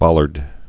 (bŏlərd)